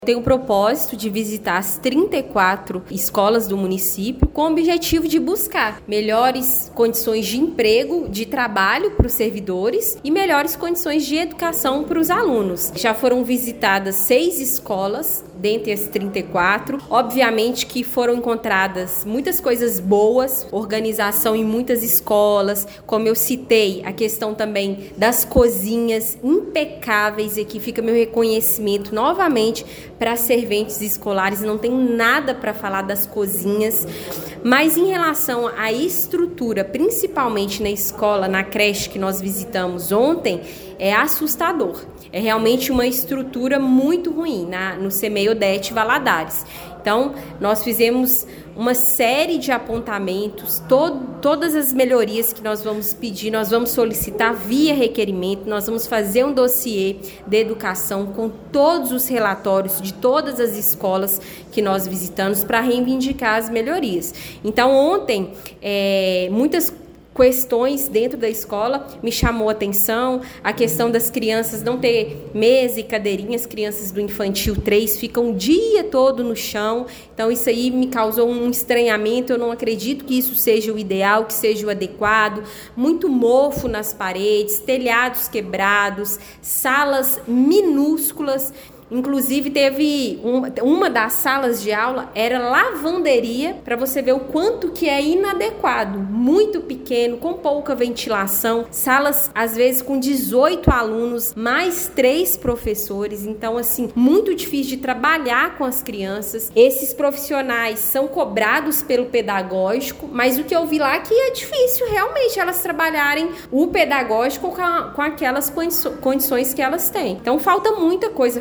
A qualidade do ensino e a infraestrutura das instituições municipais de Pará de Minas foram o centro das atenções durante reunião da Câmara Municipal, realizada ontem (07).